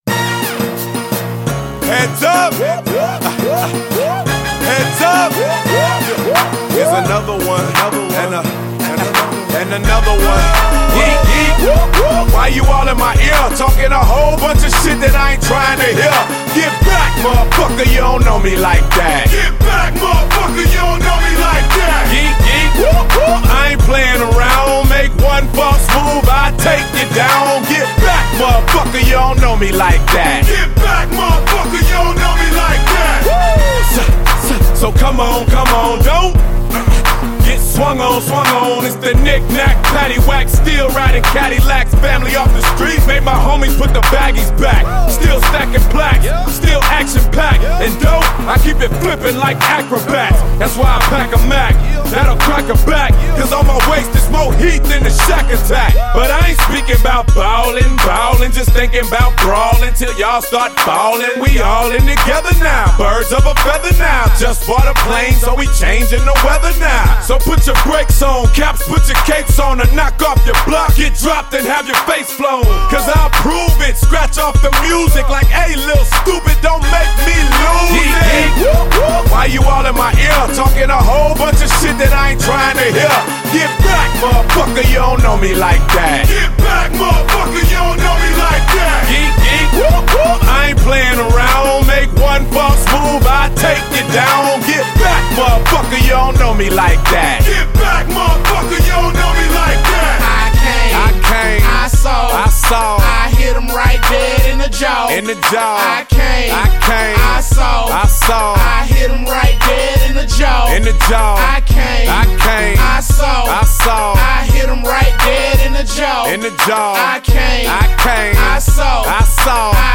HipHop 2000er